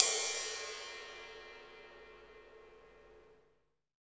CYM RIDE309R.wav